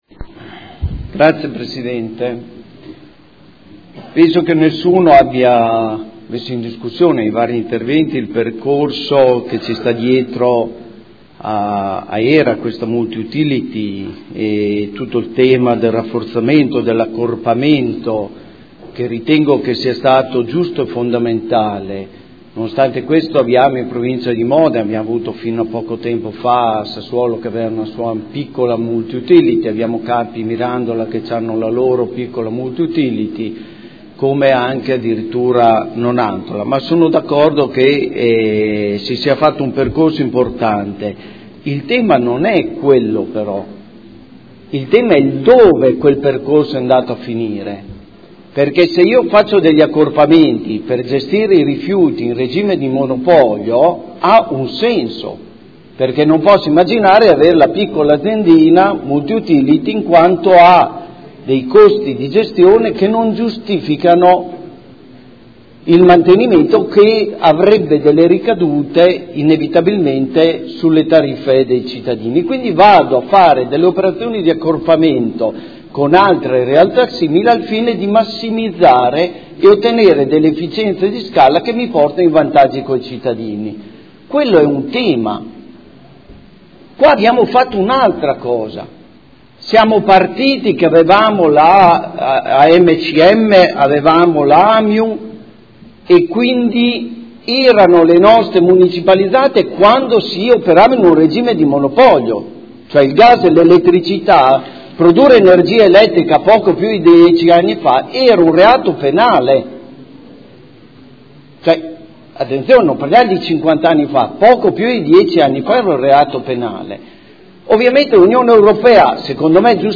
Antonio Montanini — Sito Audio Consiglio Comunale